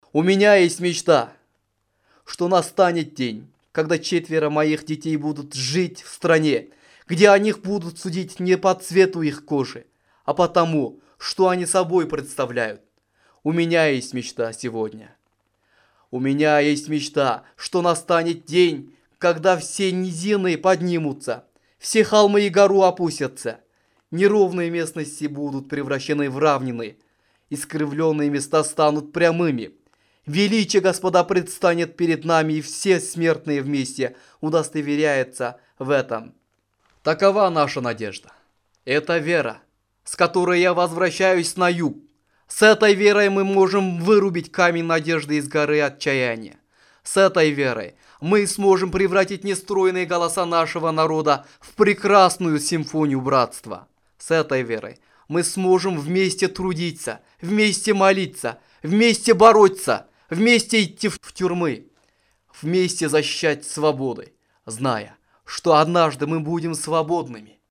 Аудиофайл представлен на конкурс "iDream" в честь 50-летия речи Мартина Лютера Кинга "У меня есть мечта". Для участия в конкурсе необходимо было записать отрывок из речи Мартина Лютера Кинга «У меня есть мечта» на кыргызском, русском или английском языке.